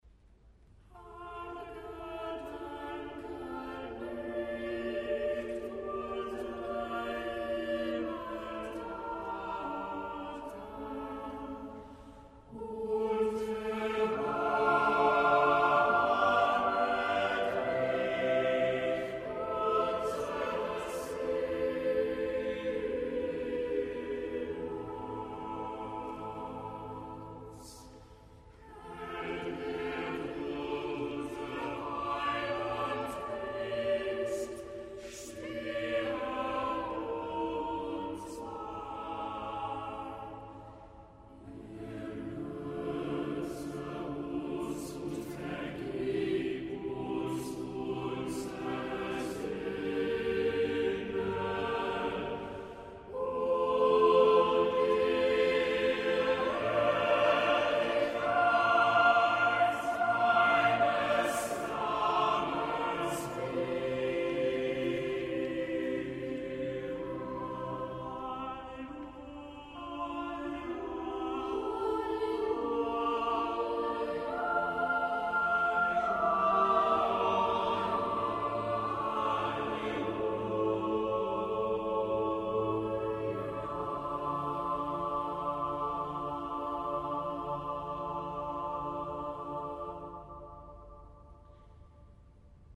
Easter from King’s College, Cambridge
4          Choir: